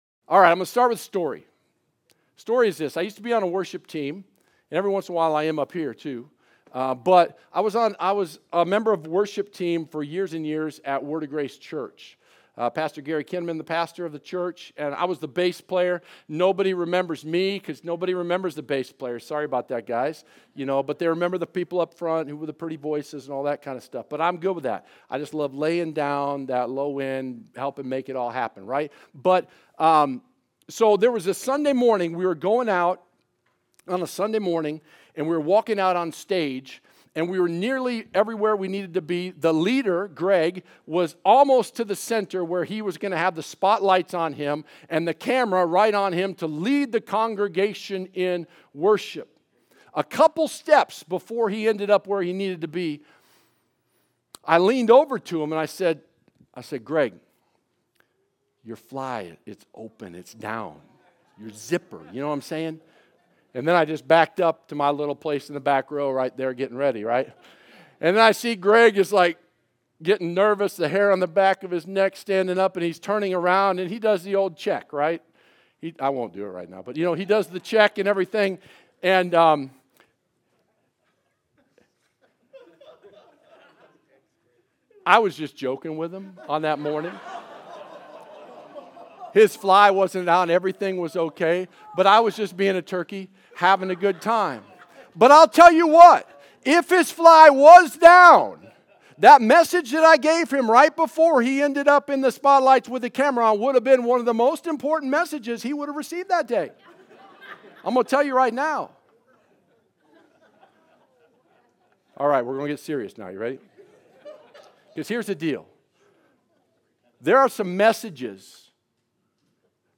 Sermon Archive (2016-2022) - Evident Life Church | Gospel-Centered, Spirit Filled Church in Gilbert, AZ